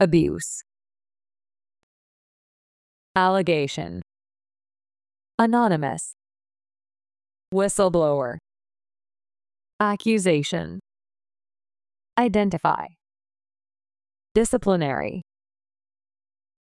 音声を再生し、強勢のある母音（＝大きな赤文字）を意識しながら次の手順で練習しましょう。
abuse /əˈbjuːs/（名）虐待、悪用
whistleblower /ˈwɪsəlˌbloʊər/（名）内部告発者